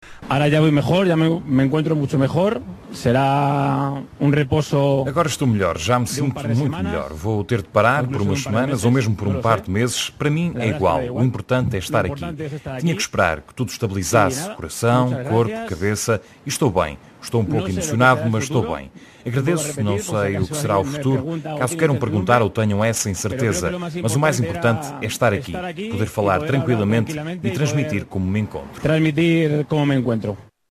Foi um Iker Casillas visivelmente emocionado aquele que se dirigiu aos jornalistas depois de receber alta médica do Hospital da CUF, no Porto, onde esteve internado desde a passada quarta-feira, na sequência do enfarte do miocárdio que sofreu durante um treino do FC Porto.
Numa declaração sem espaço para perguntas, partiu do guardião uma palavra sobre o futuro profissional.